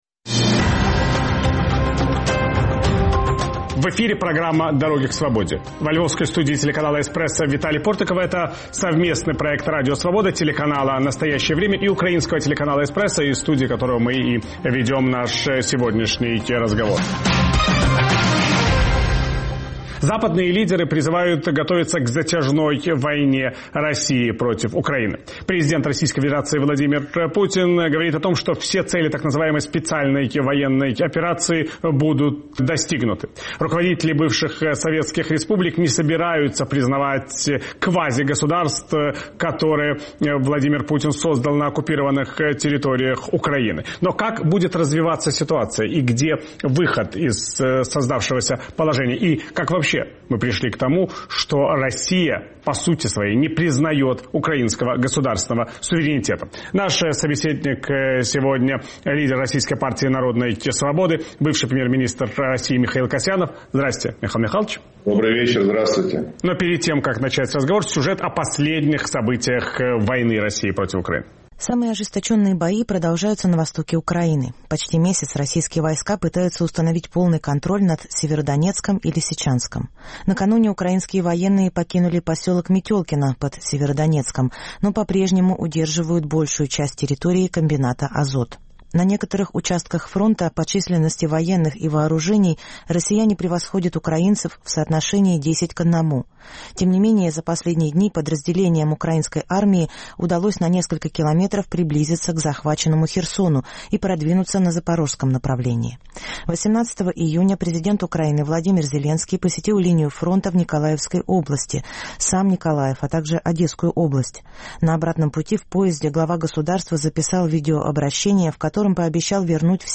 Удастся ли когда-нибудь преодолеть пропасть, которая возникла между русским и украинским народами в результате развязанной Владимиром Путиным войны? Виталий Портников беседует с бывшим премьер-министром России Михаилом Касьяновым.